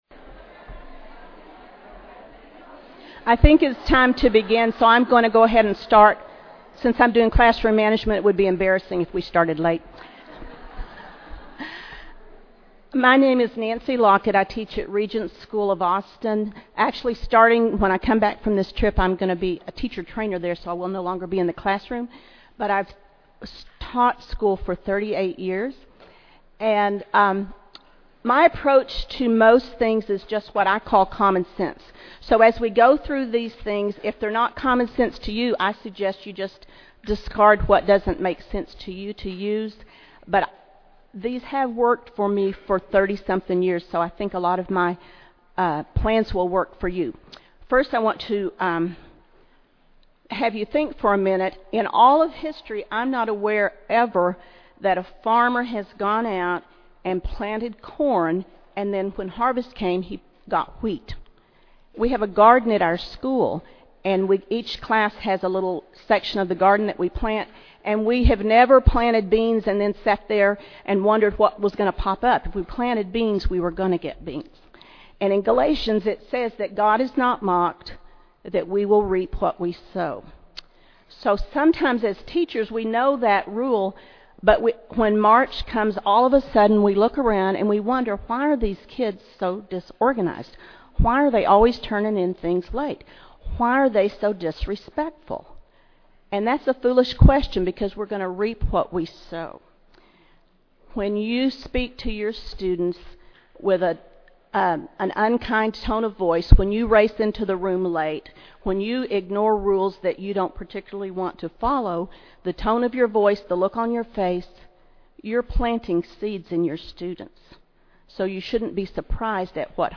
2009 Workshop Talk | 0:34:33 | Leadership & Strategic